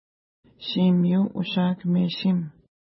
52.2069 Pronunciation: ʃi:mi:u uʃa:kəmeʃim Translation